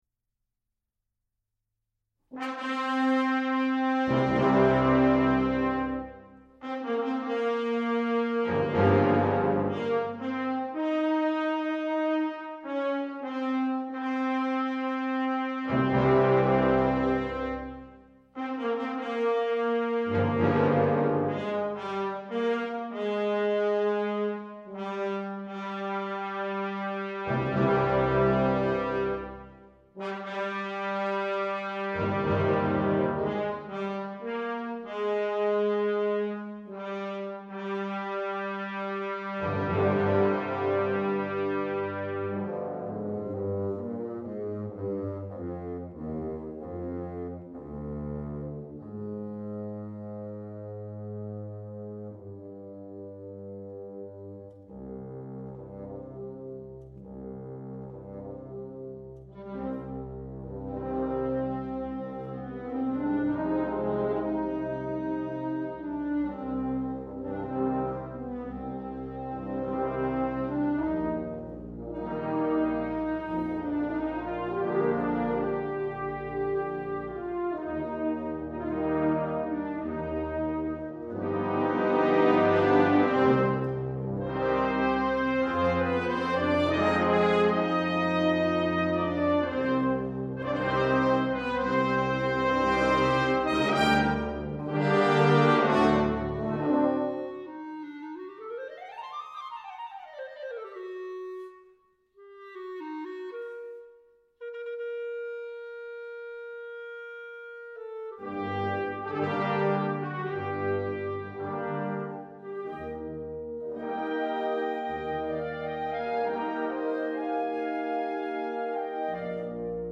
An orchestrated version
Hungarian Rhapsody No. 2 , performed by the U. S. Navy Band's Concert Band